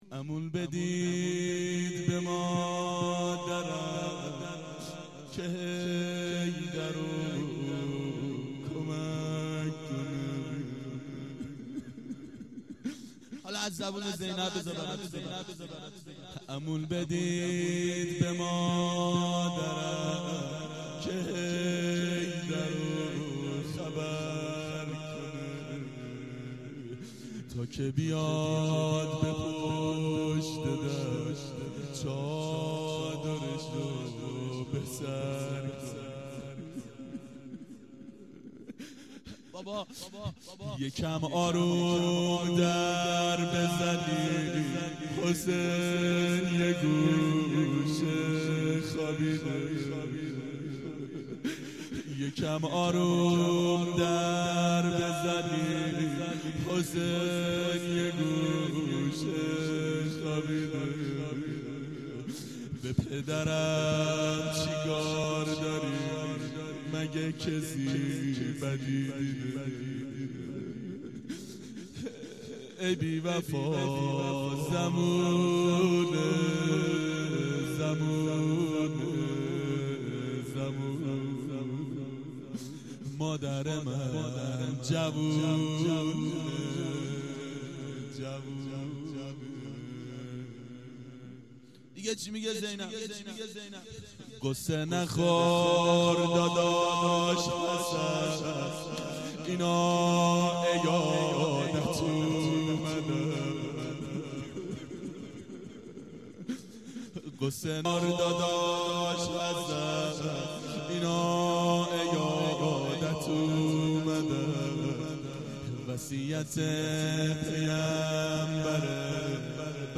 روضه حضرت زهرا سلام الله علیها
هیئت شیفتگان حضرت رقیه سلام الله علیها (شام غریبان)